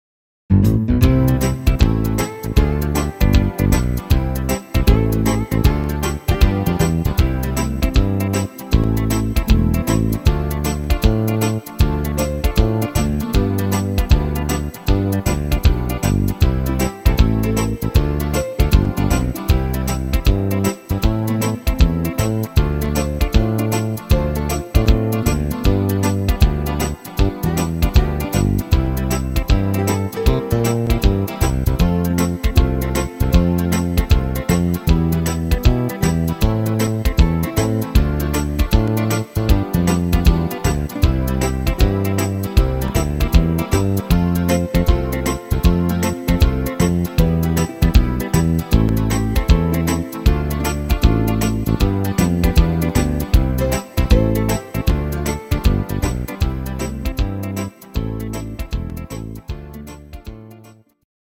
Rhythmus  Foxtrott
Art  Italienisch, Oldies, Schlager 60er